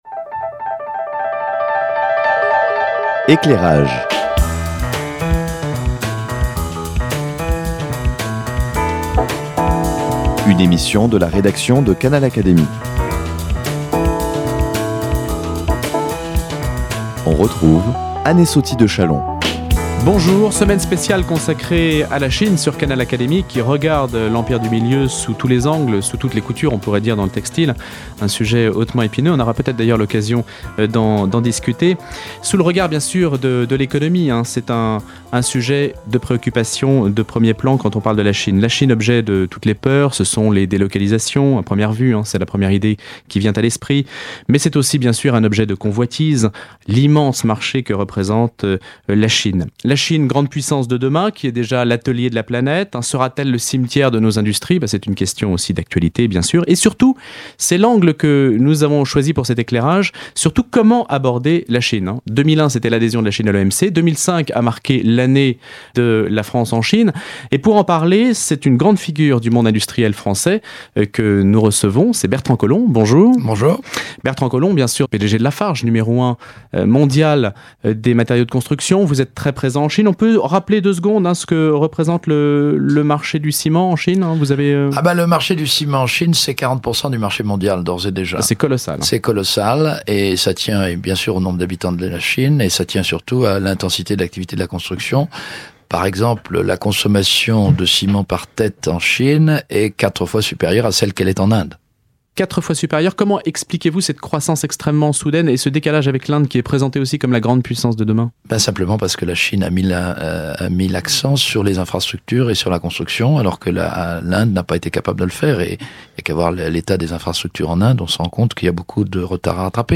Bertrand Collomb, ancien président de Lafarge, n°1 mondial des matériaux de construction répond aux questions suivantes : Comment aborder le marché chinois ?